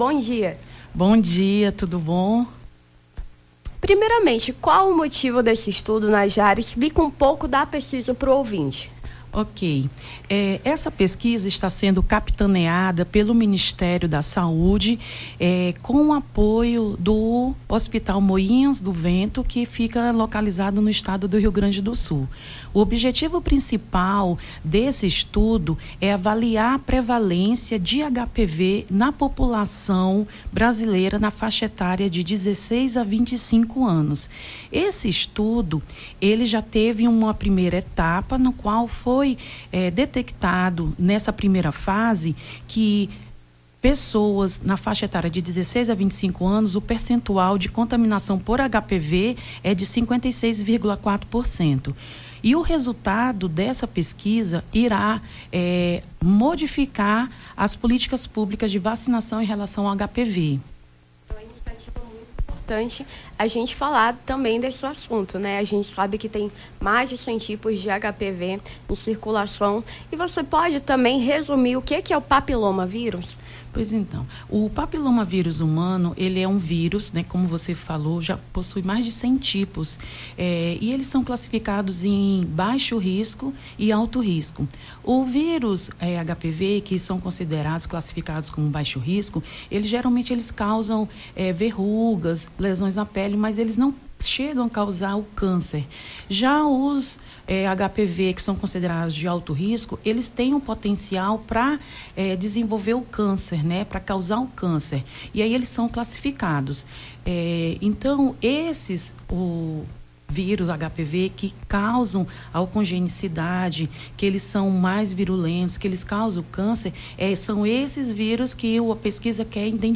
Nome do Artista - CENSURA - ENTREVISTA (EXAME DETECTACAO HPV) 25-07-23.mp3